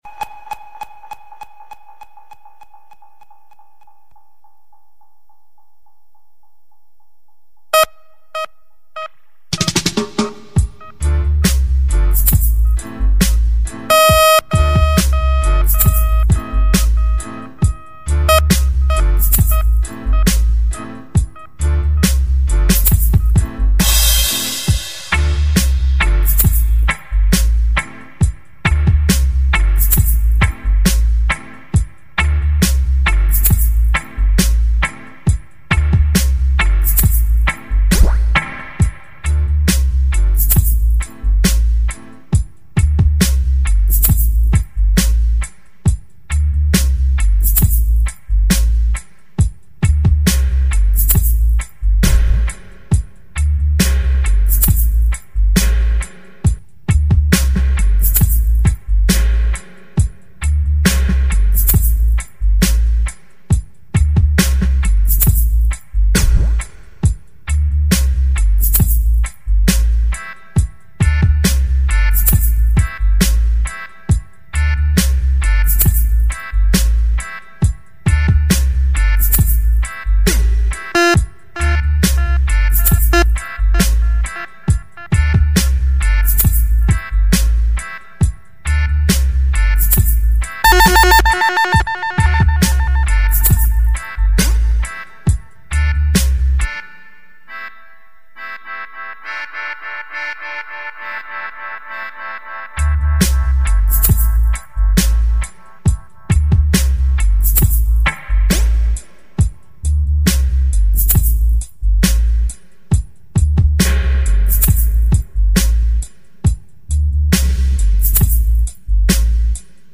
puppet-master-dub-GbZlj